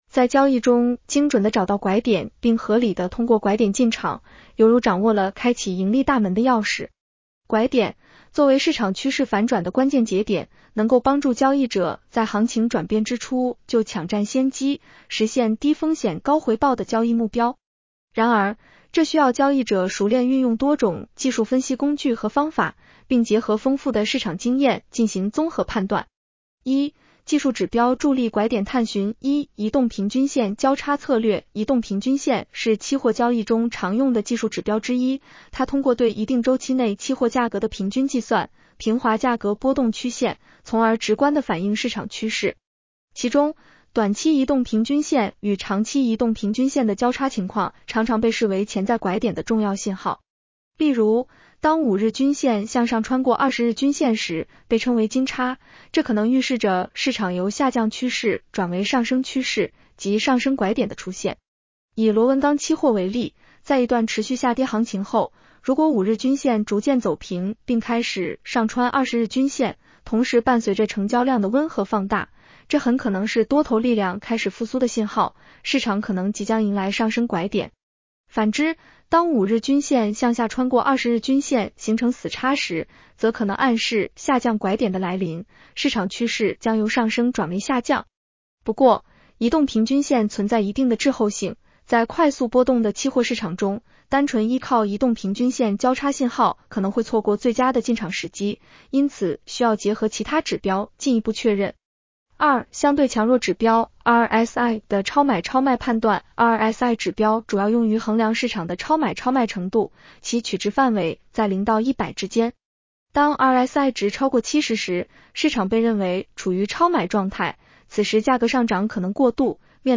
【期货交易夜读音频版】 女声普通话版 下载mp3 在交易中精准地找到 “拐点” 并合理地通过拐点进场，犹如掌握了开启盈利大门的钥匙。“